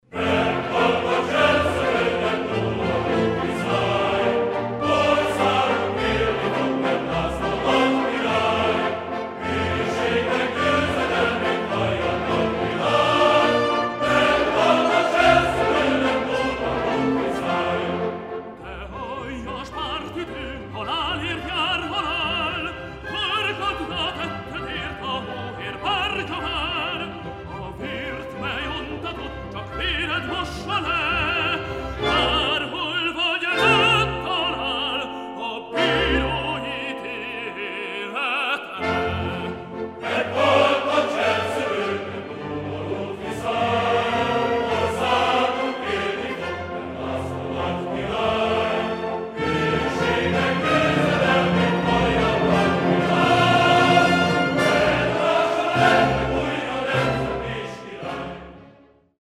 The recording was made in Budapest, in 1984..
Chorus, King